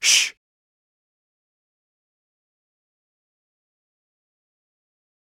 shush2.wav